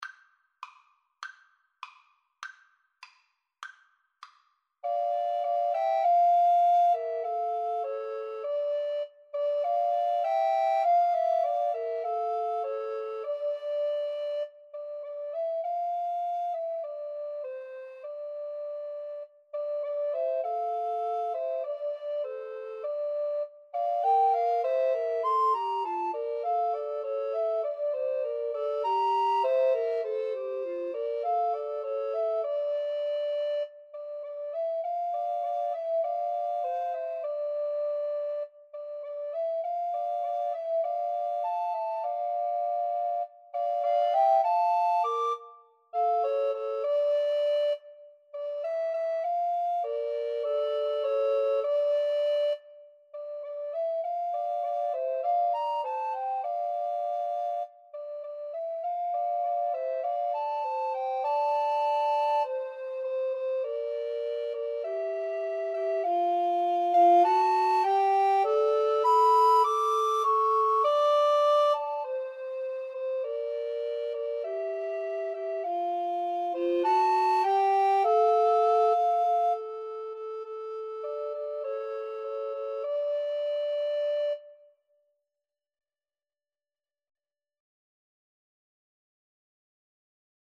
D minor (Sounding Pitch) (View more D minor Music for Recorder Trio )
Moderato
Recorder Trio  (View more Intermediate Recorder Trio Music)
Classical (View more Classical Recorder Trio Music)